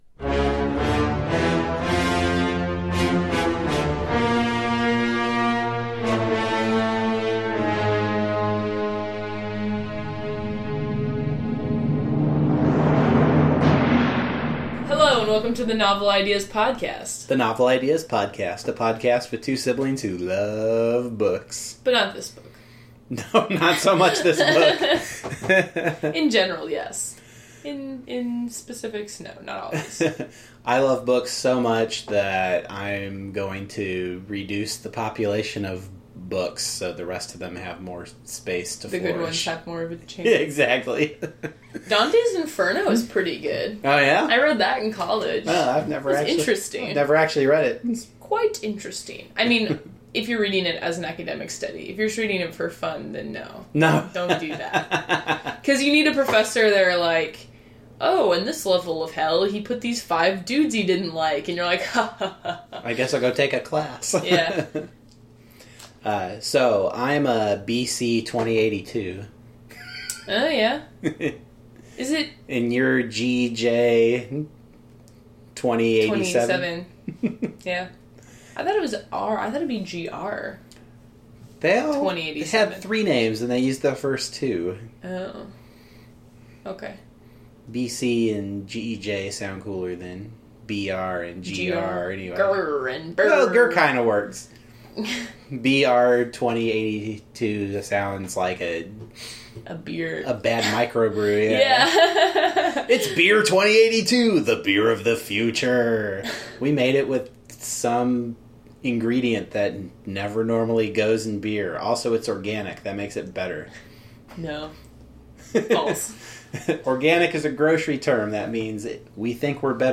The music bump is from Franz Liszt’s “Dante Symphony,” which is being performed during much of the climactic scene of this book.